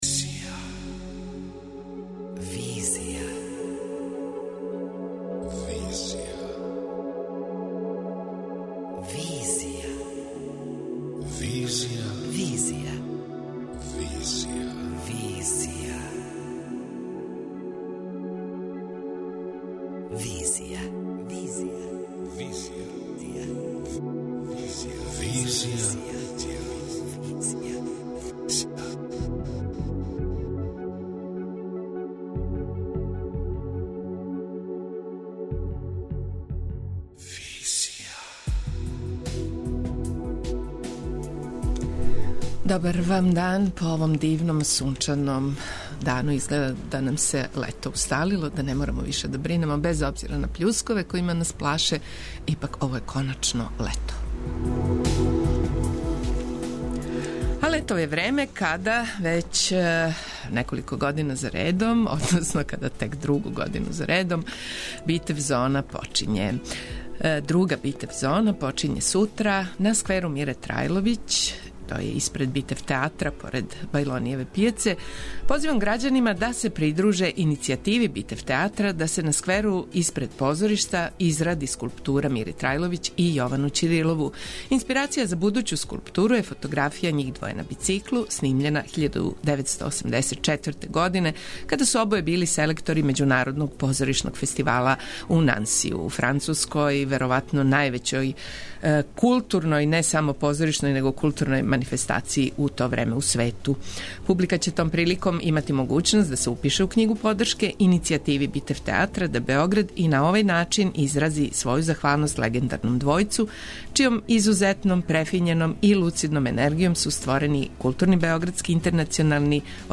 преузми : 27.12 MB Визија Autor: Београд 202 Социо-културолошки магазин, који прати савремене друштвене феномене.